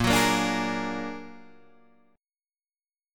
A#+7 chord